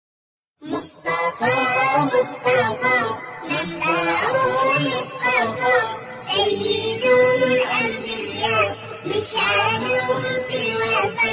Mustafa Mustafa beautiful naat❤